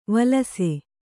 ♪ valase